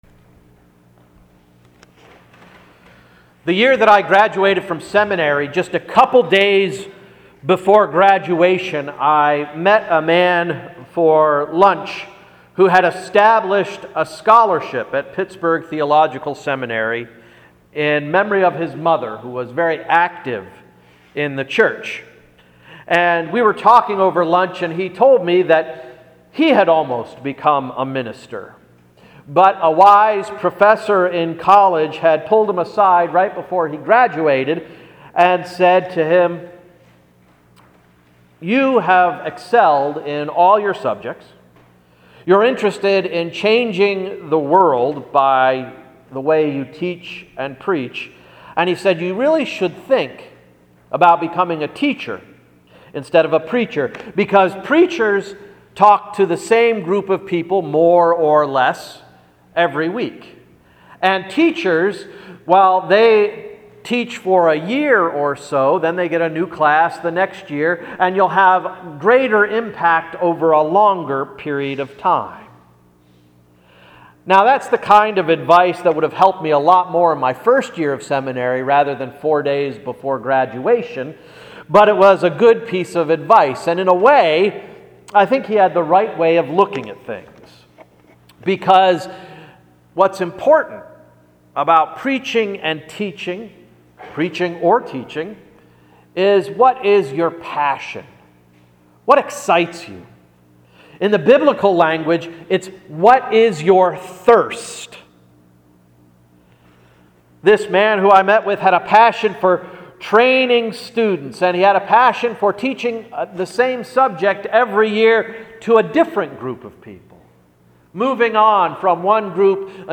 Sermon of September 28, 2014–“Thirst”